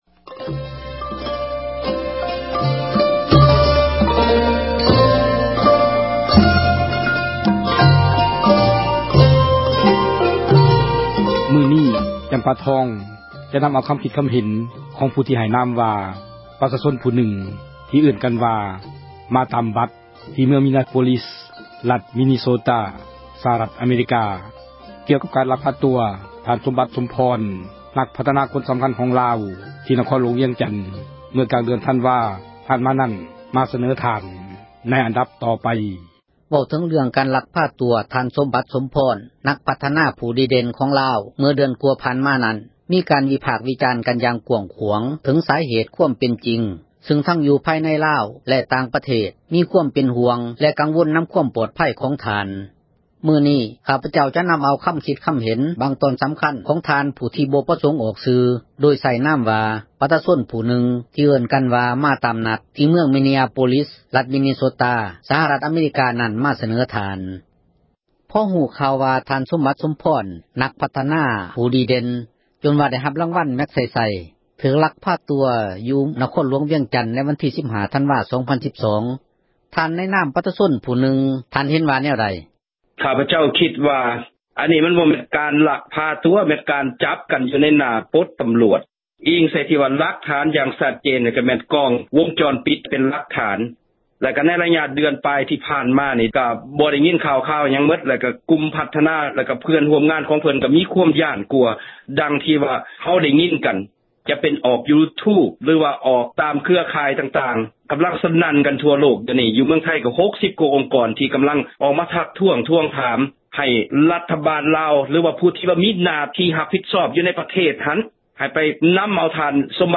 ສັມພາດຜູ້ "ມາຕາມນັດ"